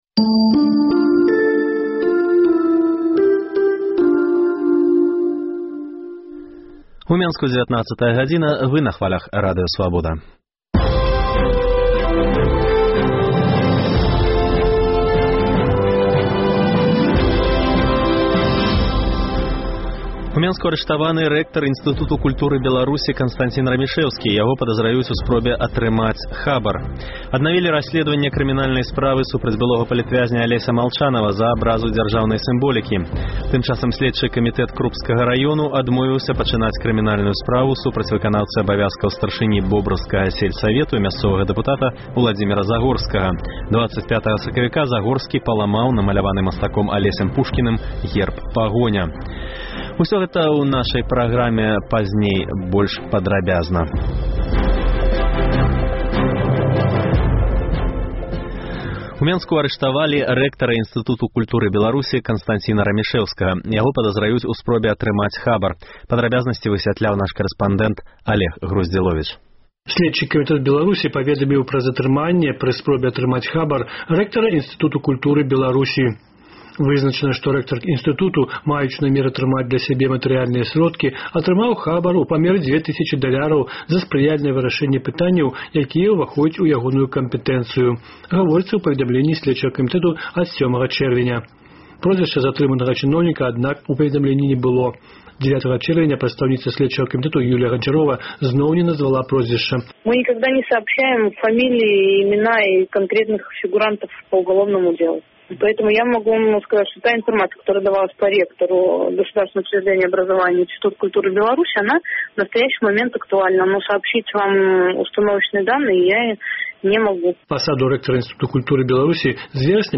Гутарка зь ім прагучыць у нашым эфіры.